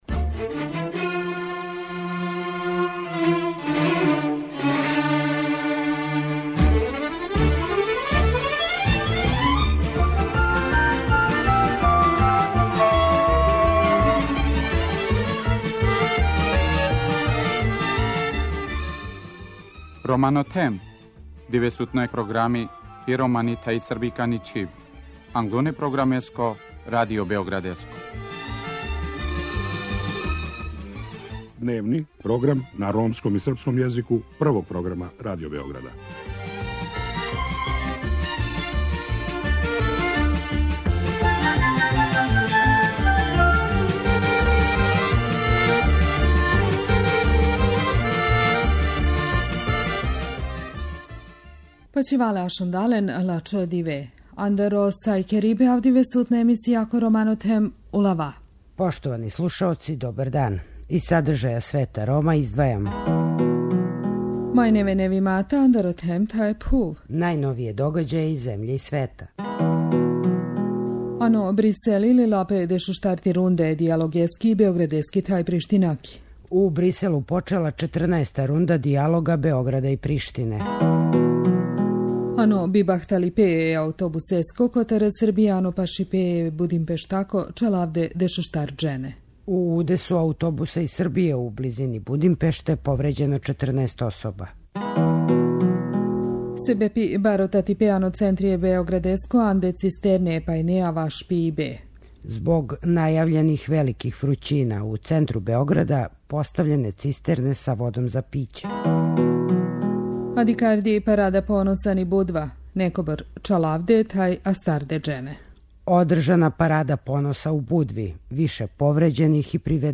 У другом делу емисије чућете репортажу о животу Рома у овој блискоисточној земљи.